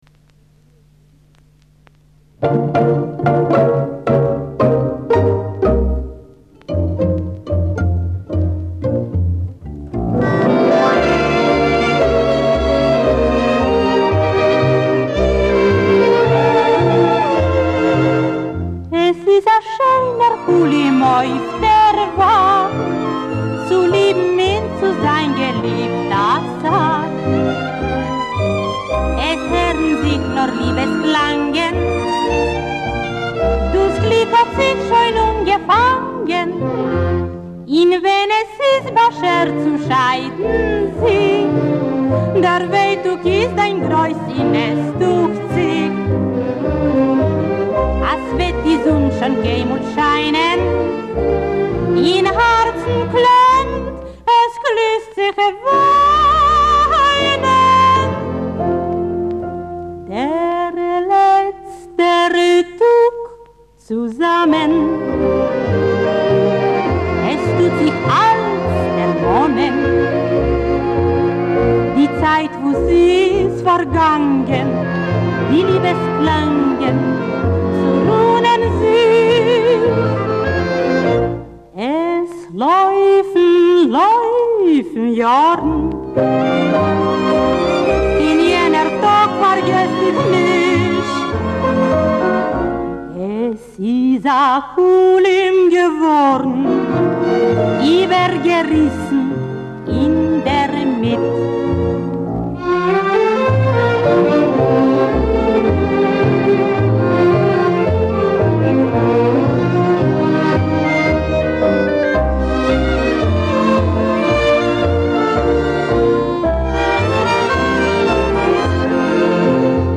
Canción de gran contenido sentimental y amor inolvidable.